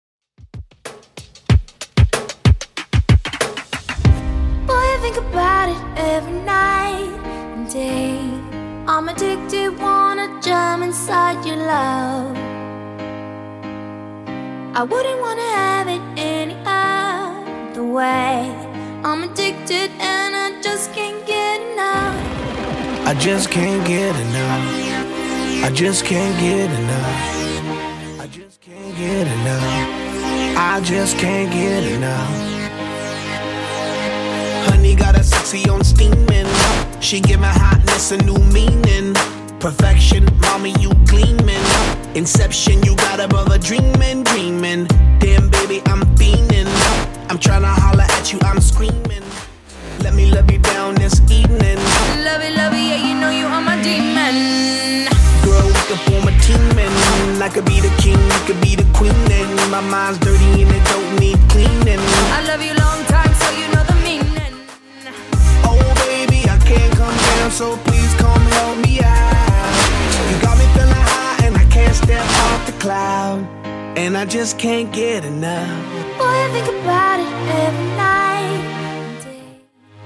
Genre: 90's
BPM: 124